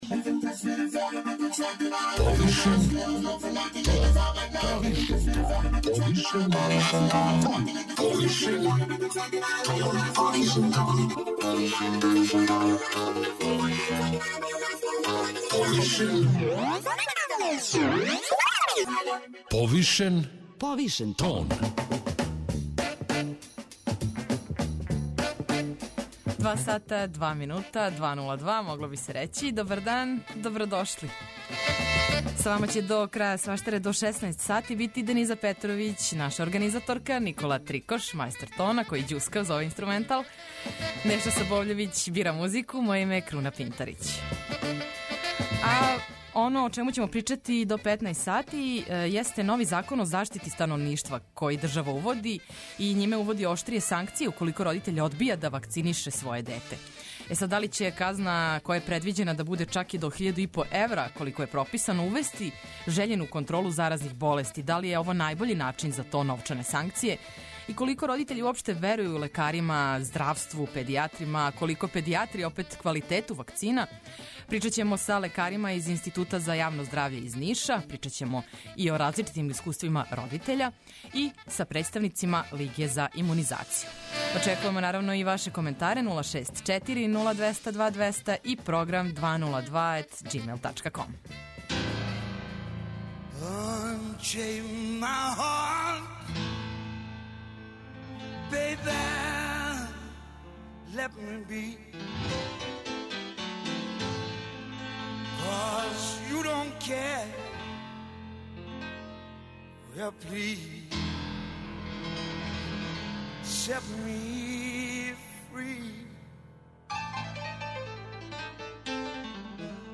Да ли ће казна и до 1.500 евра, колико је прописано, увести жељену контролу заразних болести, да ли је ово најбољи начин за то, и колико родитељи верују лекарима, здравству уопште, а колико педијатри квалитету вакцина, причамо са лекарима из Института за јавно здравље из Ниша, родитељима, и представницима Лиге за имунизацију.